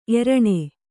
♪ eraṇe